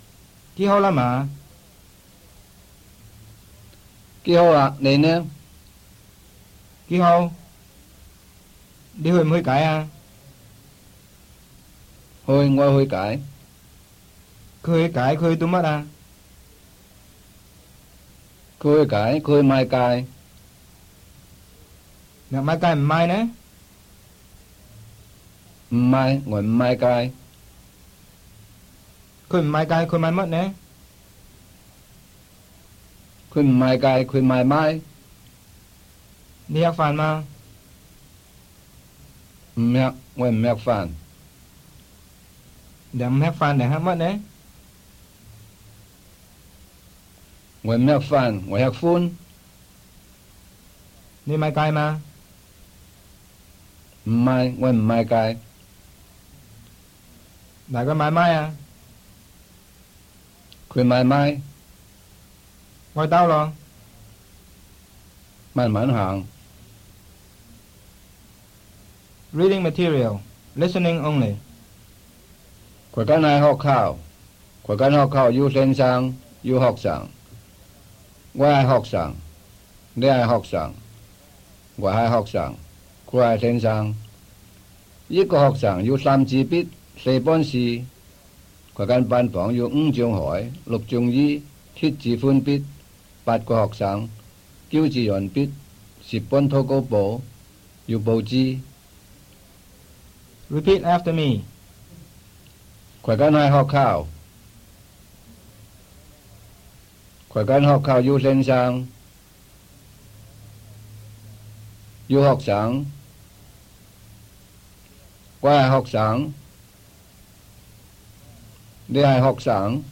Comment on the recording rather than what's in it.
*Discrepancy between the first round (Listening) and second round (Repeat after me).